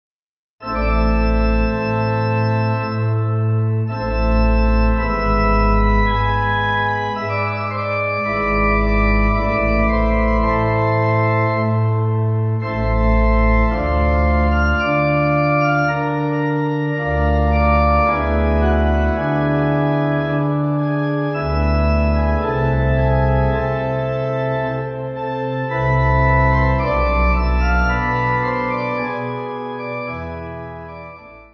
Easy Listening   C